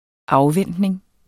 Udtale [ ˈɑwˌvεnˀdneŋ ]